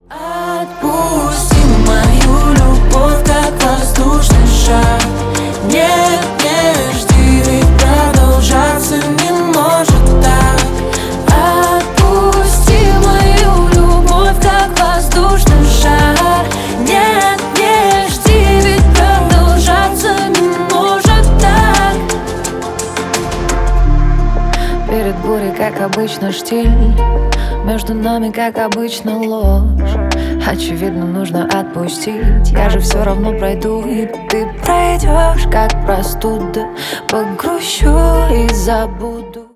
дуэт
поп